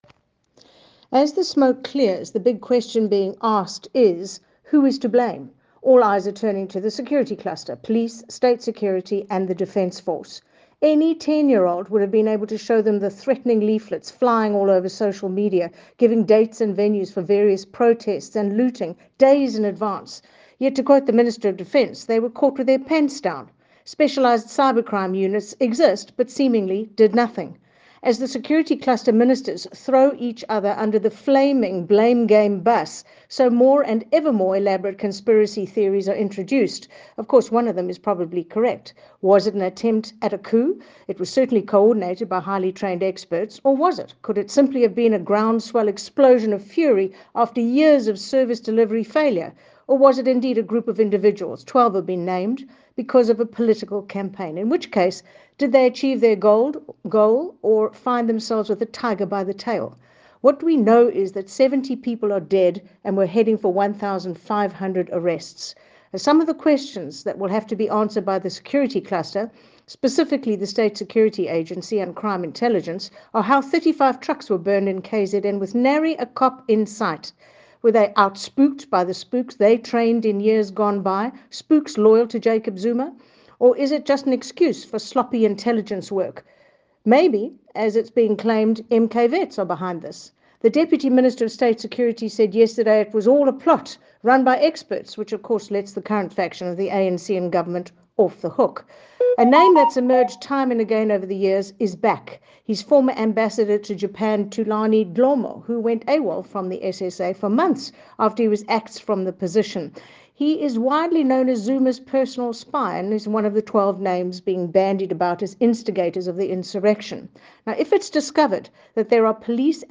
soundbite by Dianne Kohler Barnard MP.